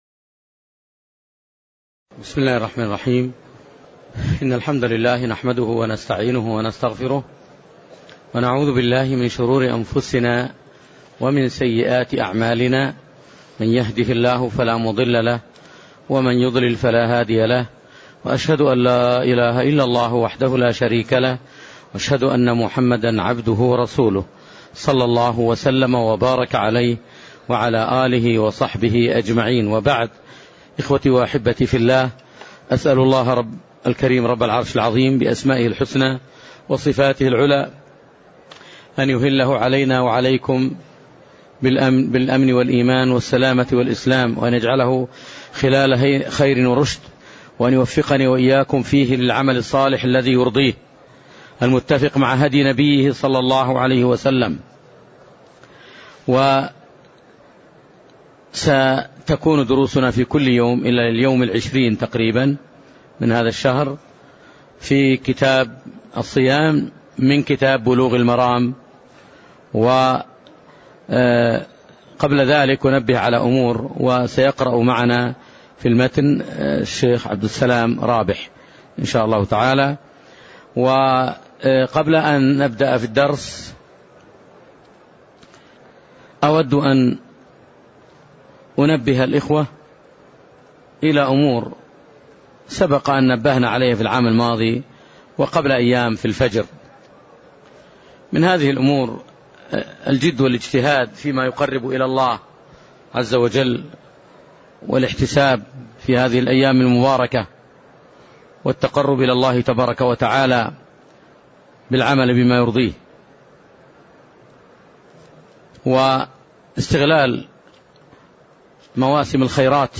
تاريخ النشر ١ رمضان ١٤٢٨ هـ المكان: المسجد النبوي الشيخ